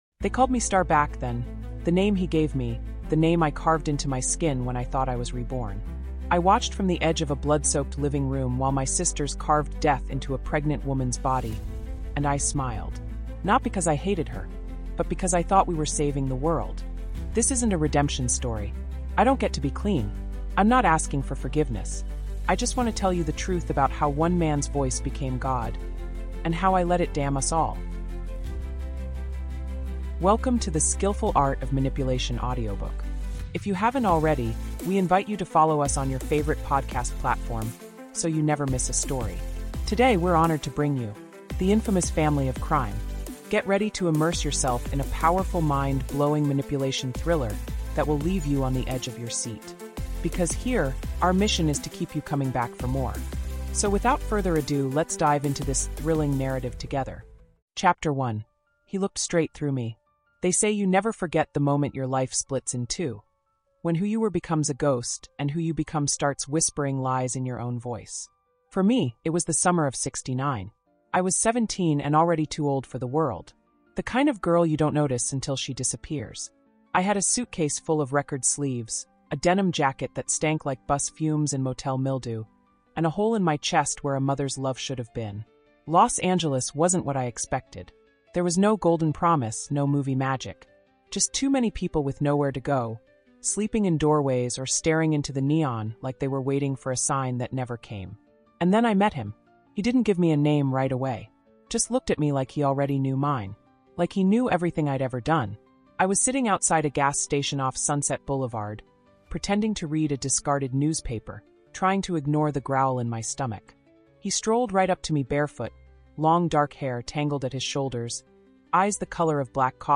The Infamous Family of Crime | Audiobook